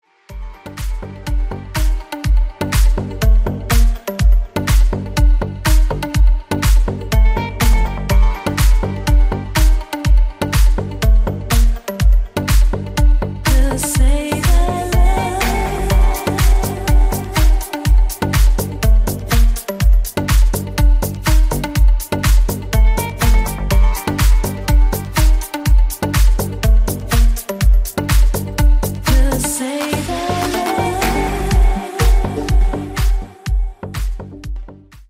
deep house
восточные мотивы
Electronic
спокойные
чувственные
nu disco
Стиль: deep house.